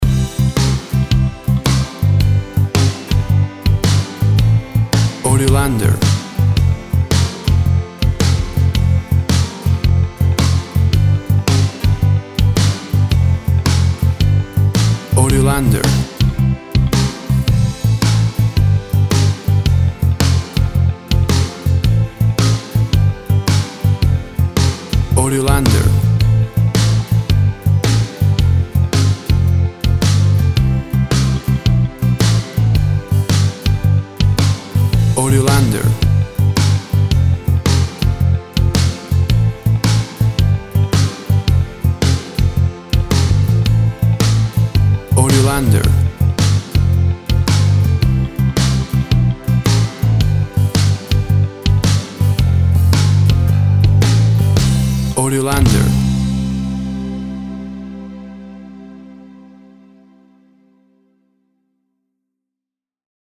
Tempo (BPM) 110